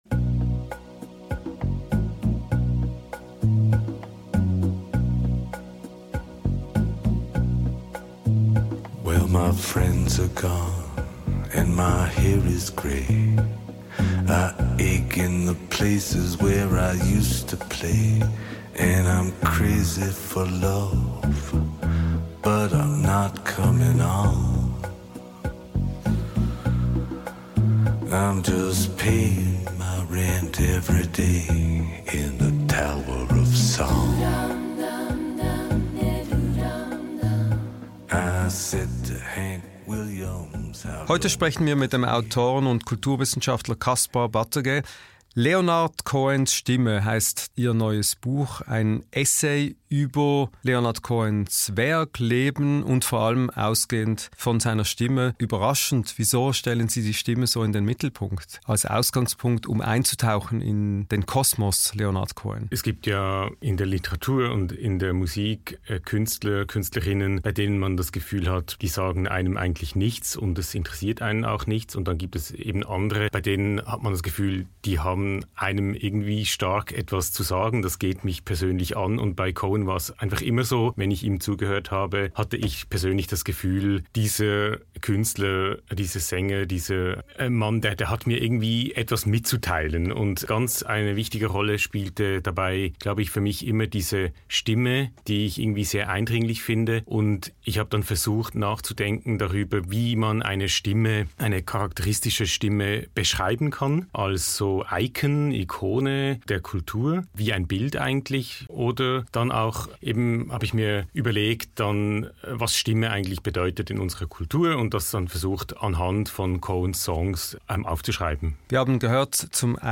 Das etwas andere Gespräch vor den Hohen jüdischen Feiertagen.